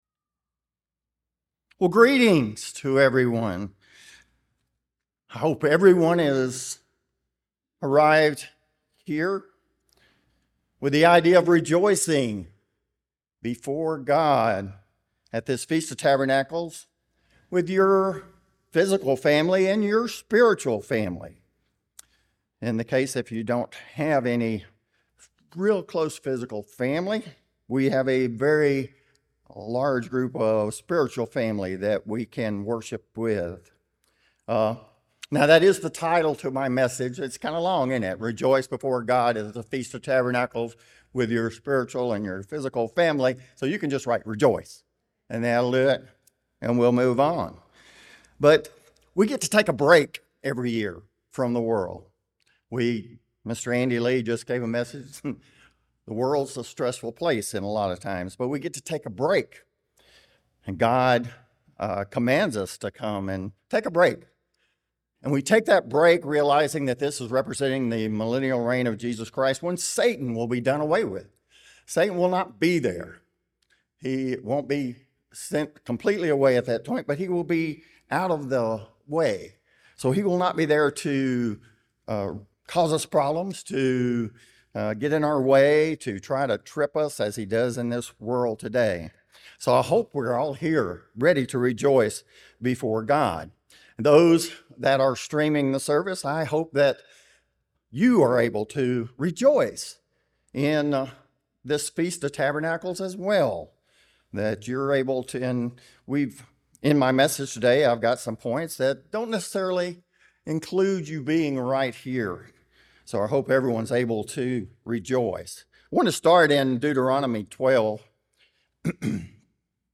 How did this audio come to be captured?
This sermon was given at the Panama City Beach, Florida 2023 Feast site.